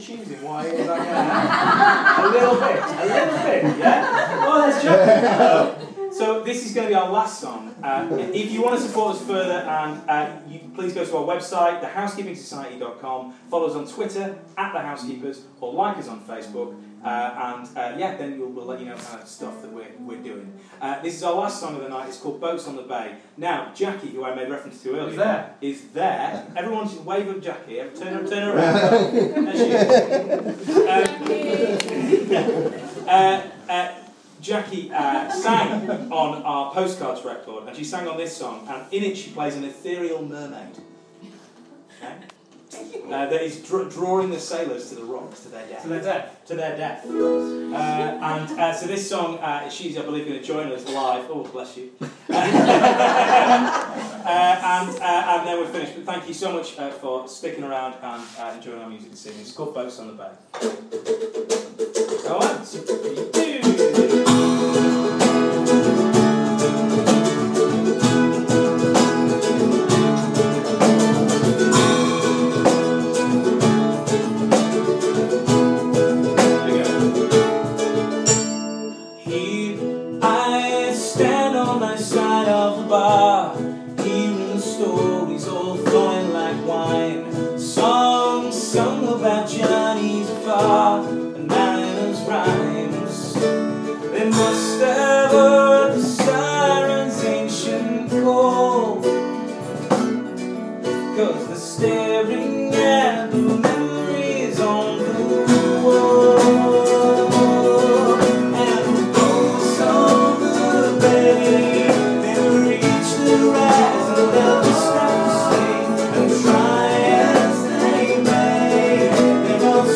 A musical soirée Ossett style.